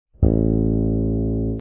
Cuerda tercera del bajo: LA (A)
La tercera cuerda está afinada en LA, o A. Es una de las zonas más utilizadas para construir líneas de bajo, grooves y acompañamientos.
cuerda-la-al-aire.mp3